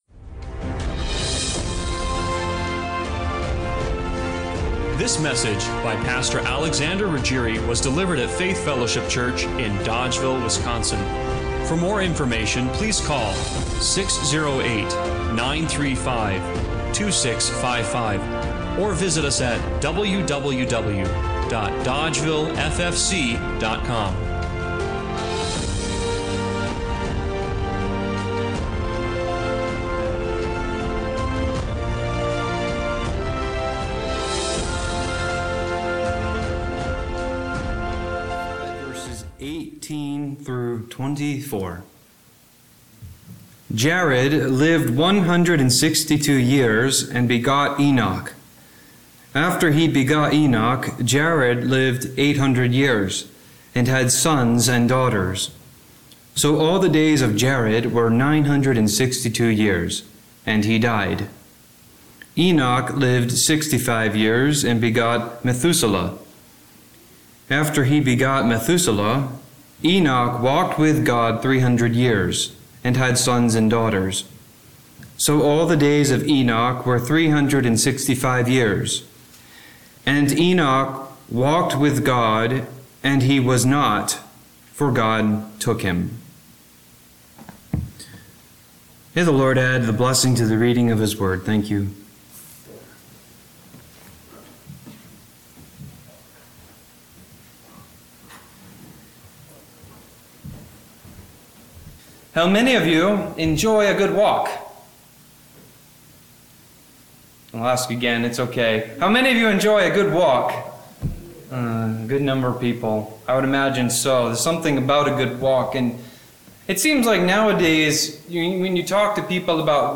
Genesis 5:18-24 Service Type: Sunday Morning Worship What if the walk of life isn’t just about where you’re going—but who you’re going with?